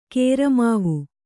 ♪ kēra māvu